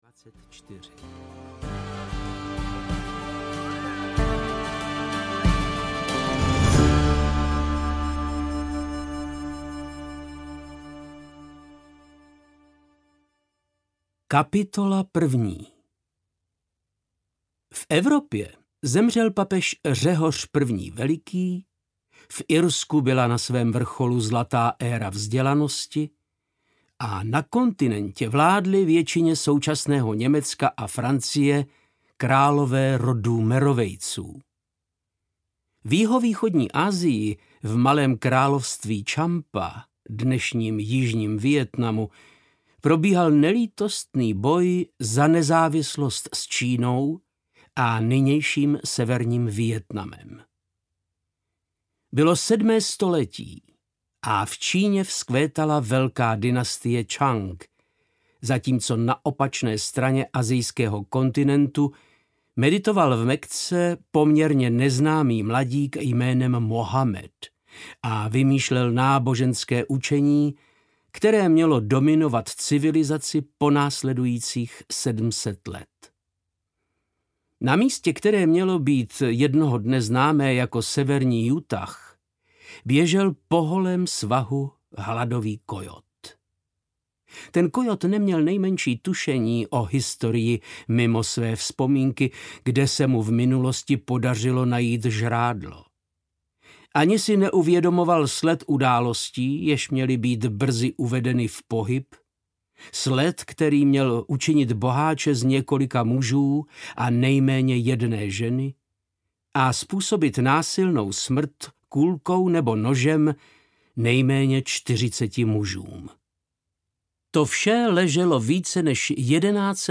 Krajina pistolníků audiokniha
Ukázka z knihy
• InterpretVáclav Knop